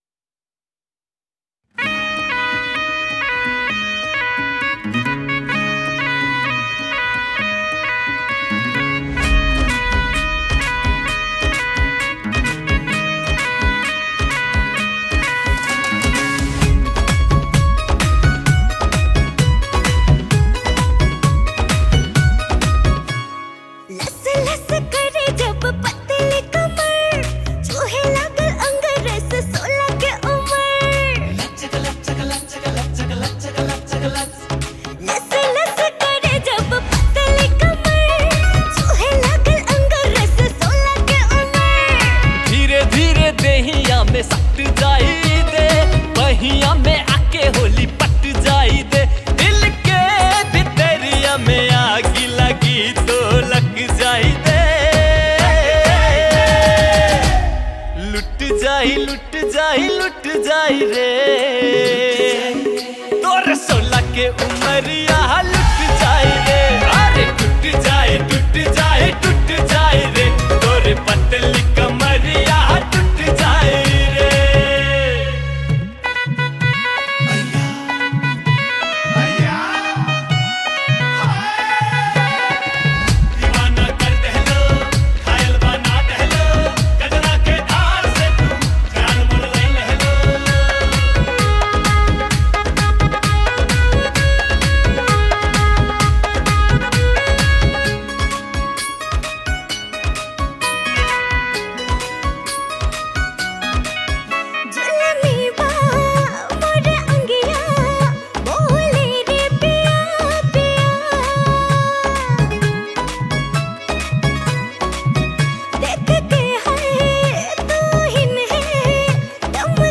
Tharu Item Dancing Song